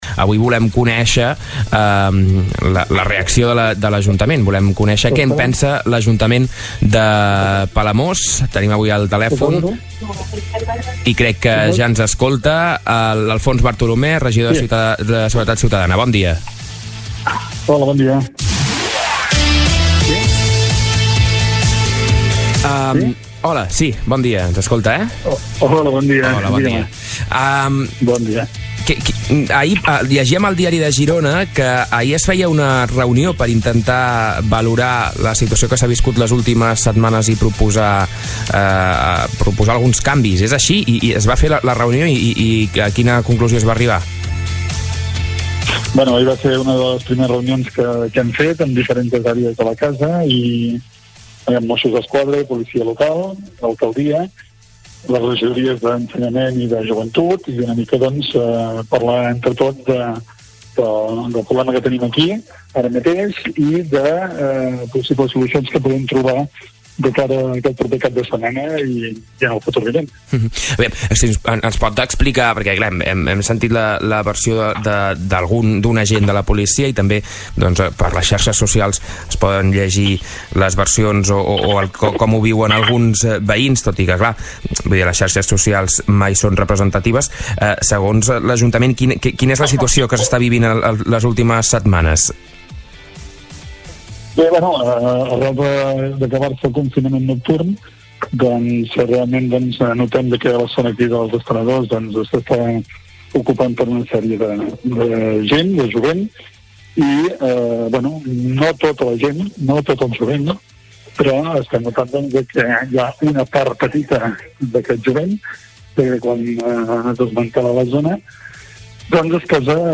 Davant el conflicte policial que hi ha actualment a Palamós, hem parlat amb Alfons Bartolomé, regidor de seguretat ciutadana de Palamós, que ha explicat el que es va pactar a la reunió del dimarts entre els diversos cossos policials, Joventut i el consistori per millorar el servei.